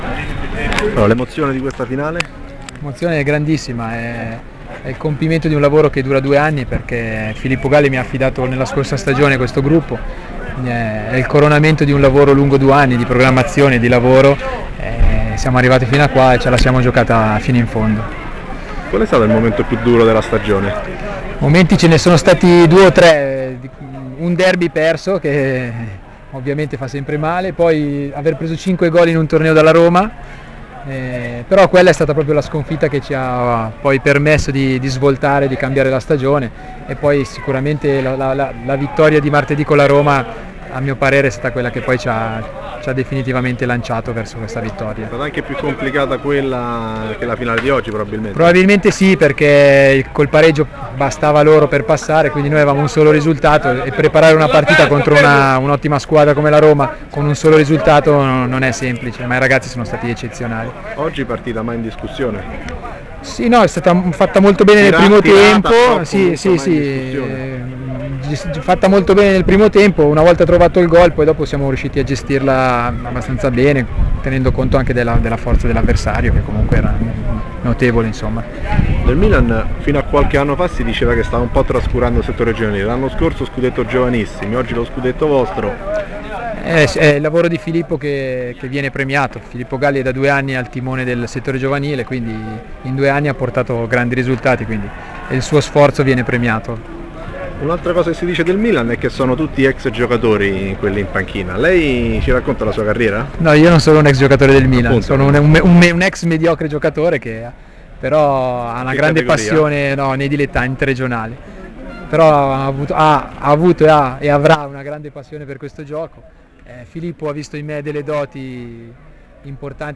LE INTERVISTE: